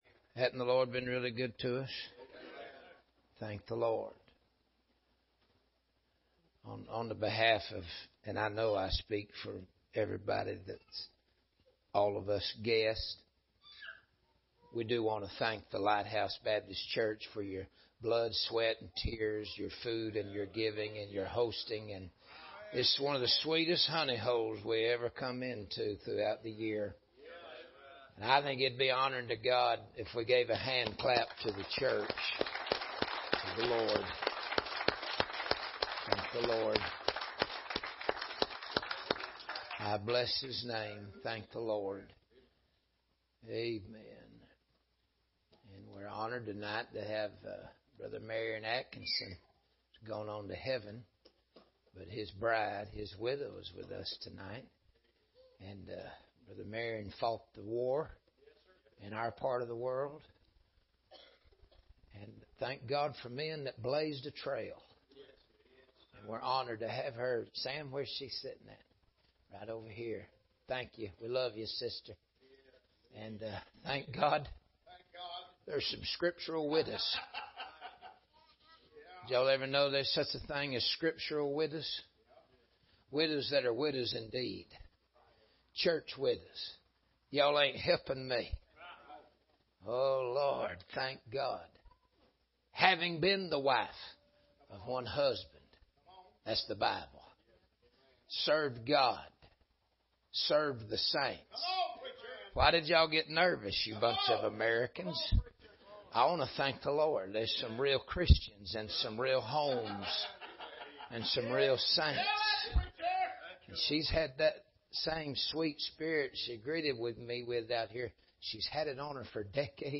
From Church: "Liberty Baptist Church"